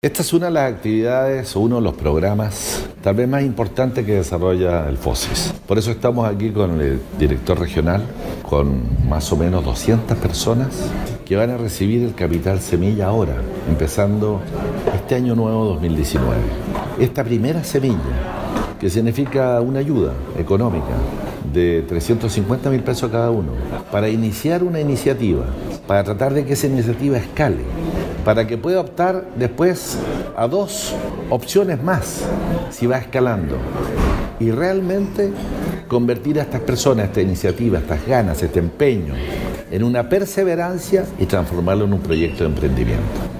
En la capital regional se realizó la clausura de los talleres que entregan las herramientas necesarias para iniciar un microemprendimiento o trabajos por cuenta propia. Así, lo explicó, el Intendente, Harry Jurgensen.